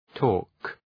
{tɔ:rk}
torque.mp3